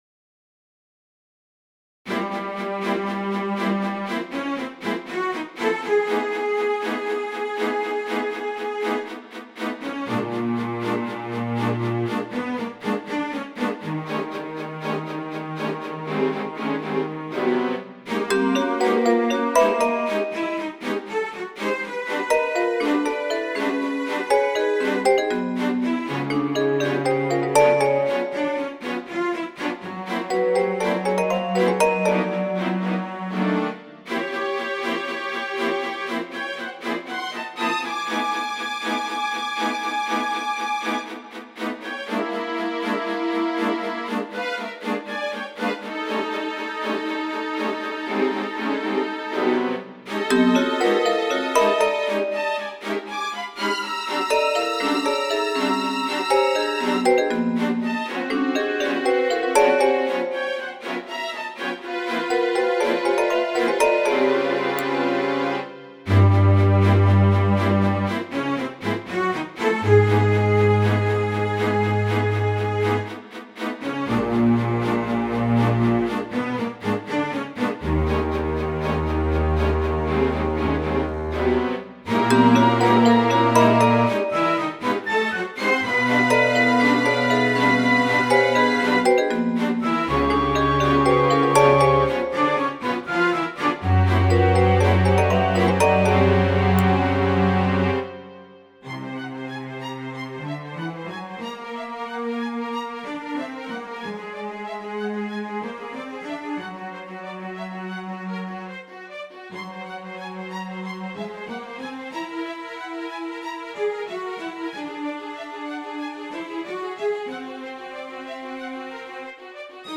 Orchestre Musique réalisée en home studio avec instruments classiques virtuels (Vienna symphonic Library, Vitous etc..)